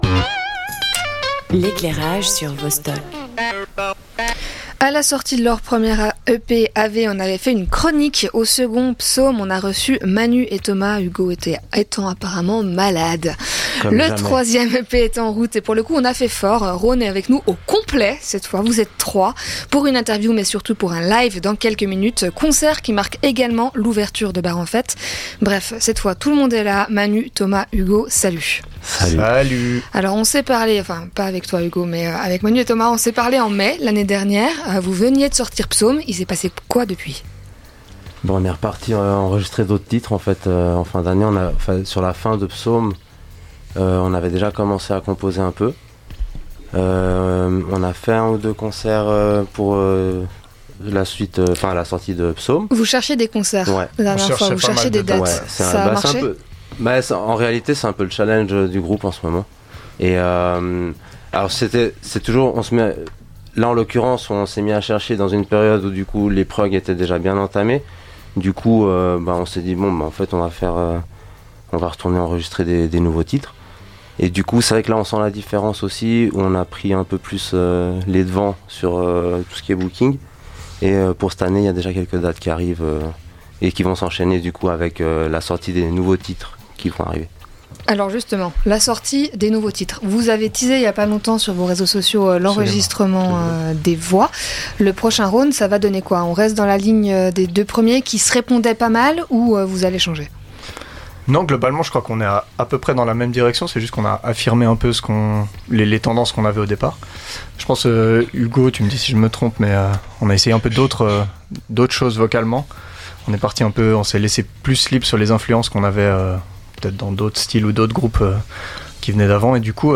Le trio Rhône en interview